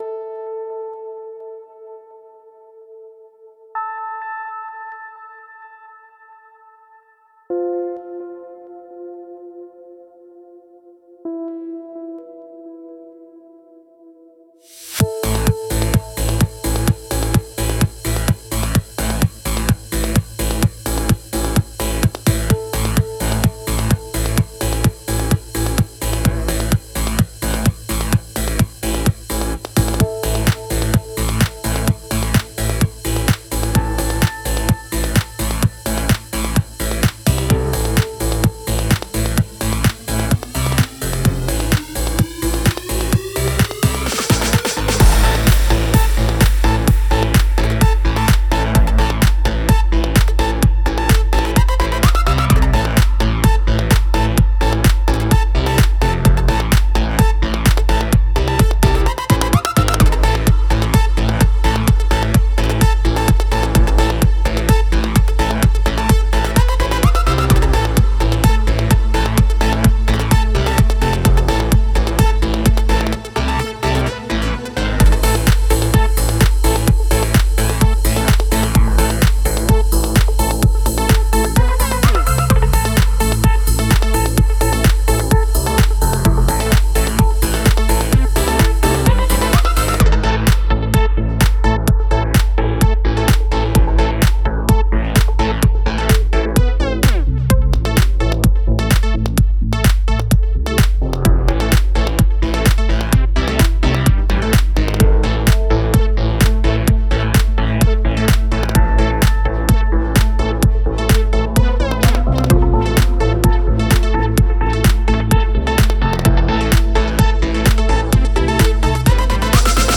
Techno /Electro Neuer Track mit neuem Equipment und anderem Workflow